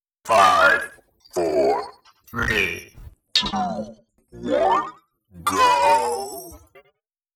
Countdown_5-go.wav